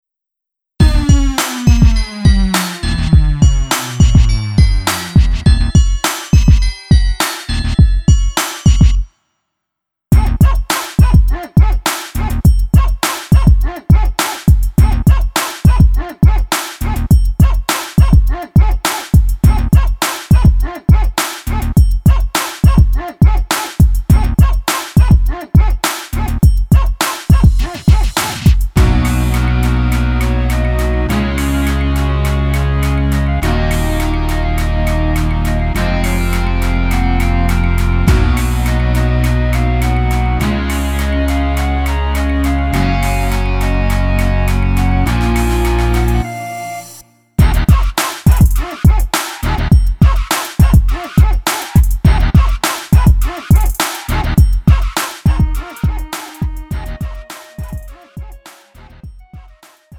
음정 -1키 3:11
장르 가요 구분